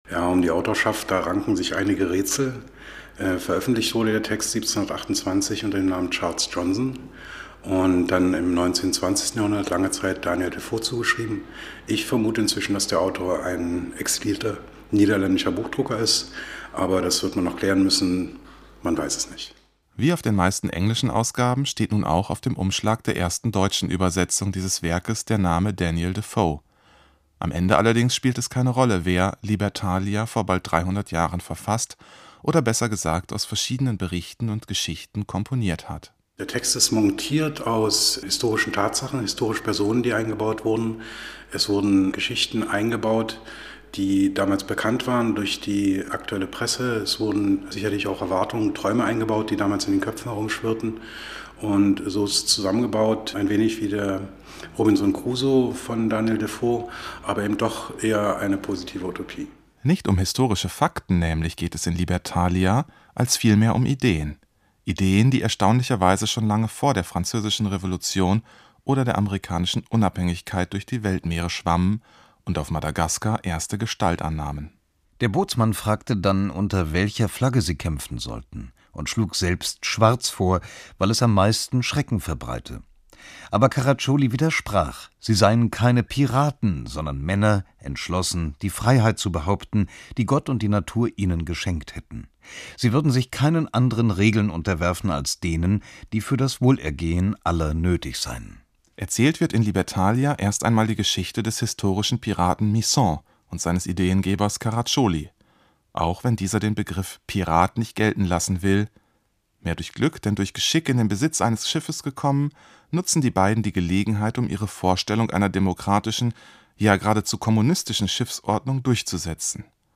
SWR2-Feature, 04.